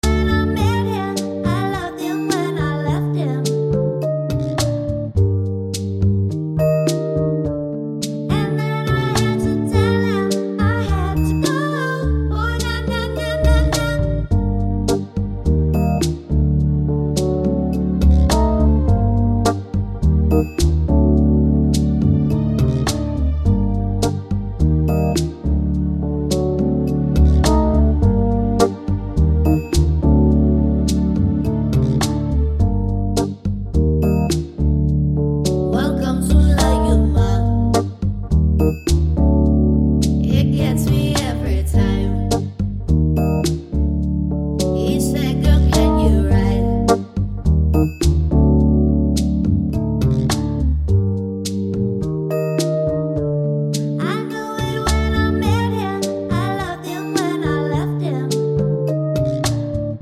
Acoustic Version with Backing Vocals